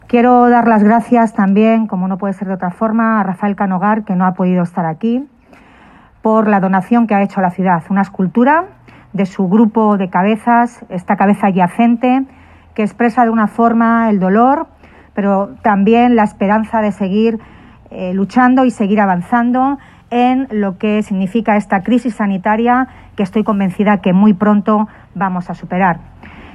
La alcaldesa de Toledo, Milagros Tolón, ha tomado la palabra en este Día de la Ciudad y San Ildefonso en el acto de inauguración de la escultura-homenaje a las víctimas de la covid-19 con el recuerdo puesto en aquellos toledanos y toledanas que han fallecido a consecuencia de la pandemia.
Mialgros Tolón, alcaldesa de Toledo